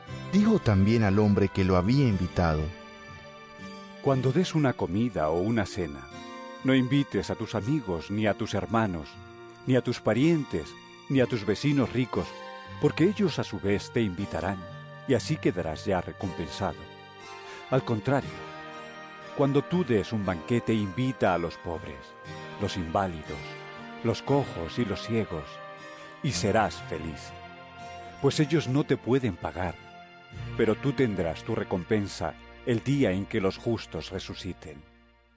Lc 14 12-14 EVANGELIO EN AUDIO